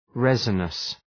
Προφορά
{‘rezənəs}